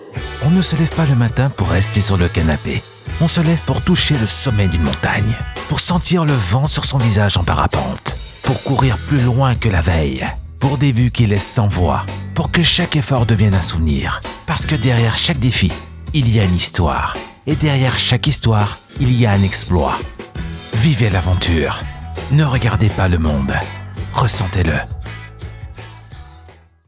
Demonstração Comercial
Narração